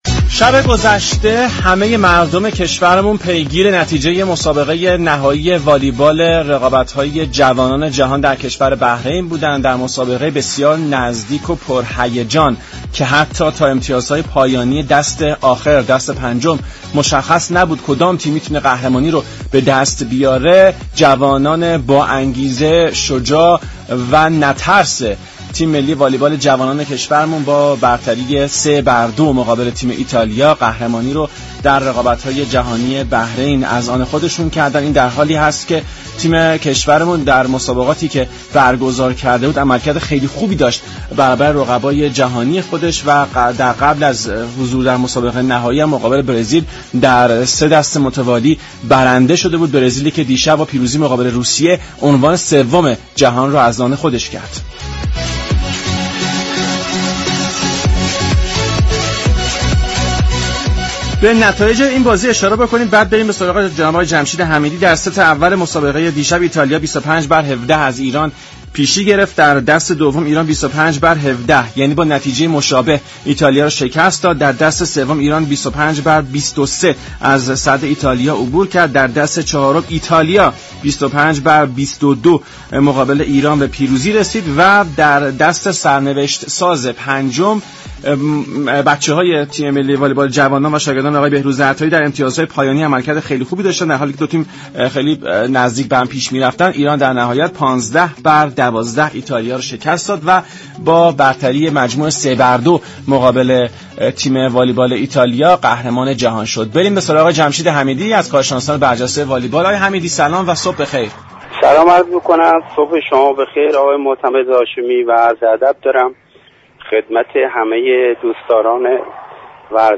یك كارشناس والیبال گفت: فدراسیون والیبال و دست اندكاران امر، اگر از نسل طلایی ایران استفاده بهینه كند قطعا در آینده موفقیت بیشتر را از آن خود خواهد ساخت.